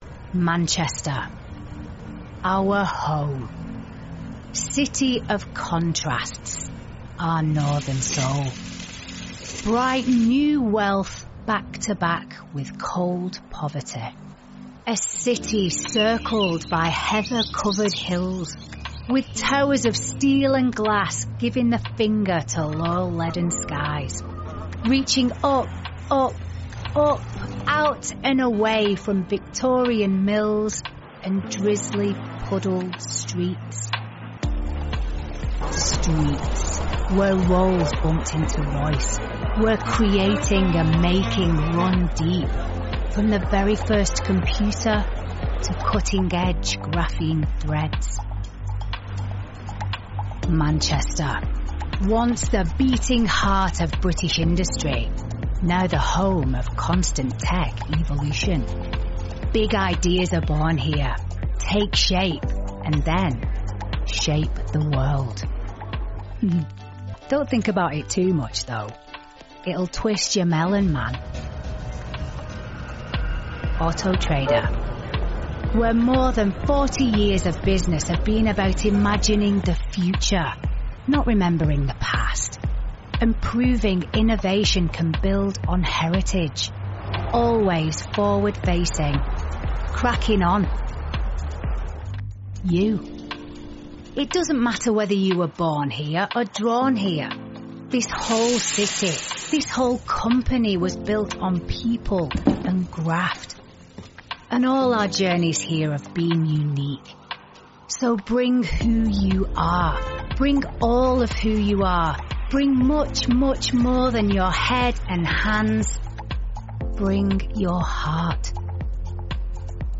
Rapide, fiable et naturellement conversationnelle, elle offre une voix off professionnelle qui captive et captive.
Narration
* Cabine de son spécialement conçue, isolée et traitée acoustiquement
* Micro et protection anti-pop Rode NT1-A